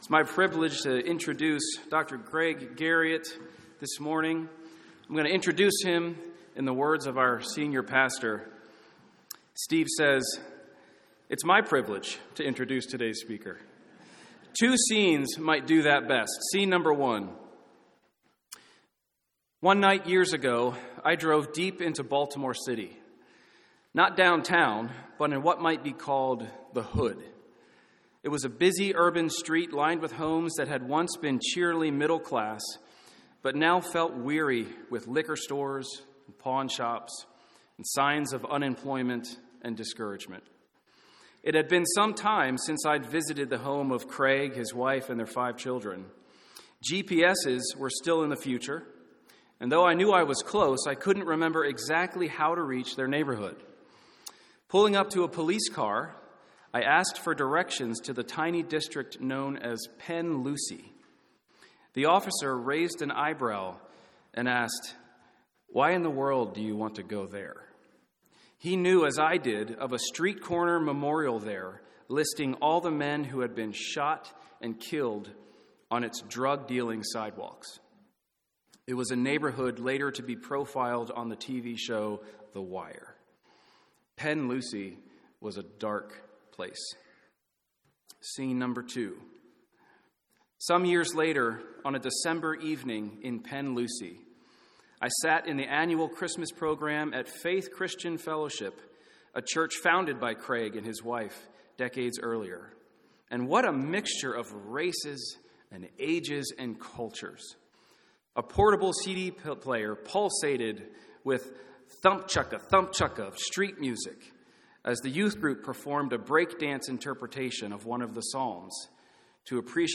2022 Sermon Outline 1.